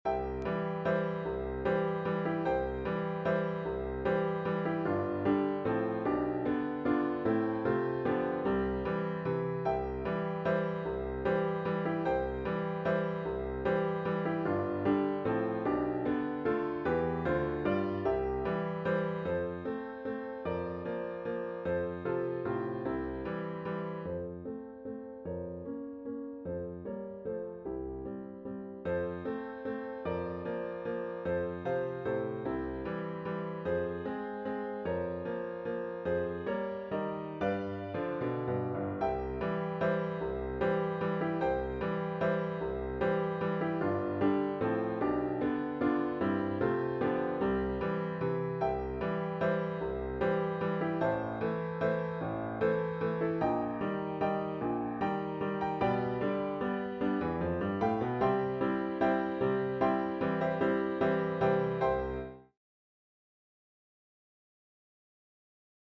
In I finally share that other new Little Waltz…